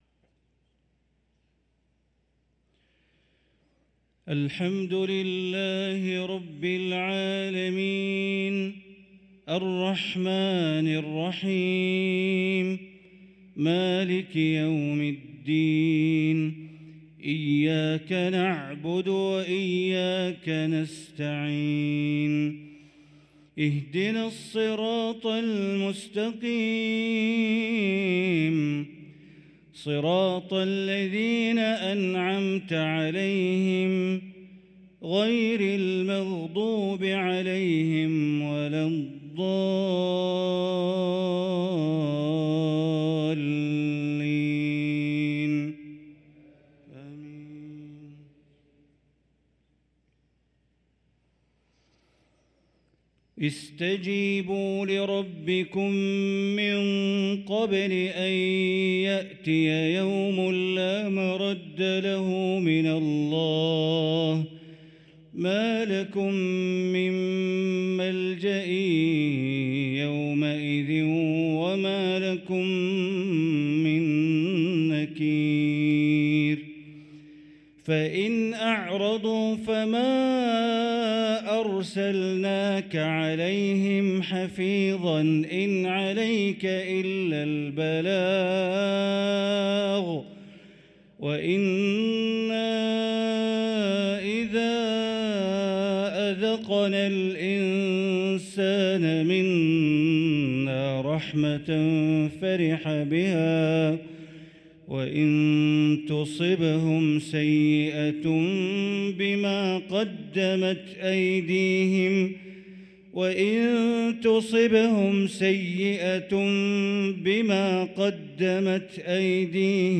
صلاة المغرب للقارئ بندر بليلة 28 صفر 1445 هـ
تِلَاوَات الْحَرَمَيْن .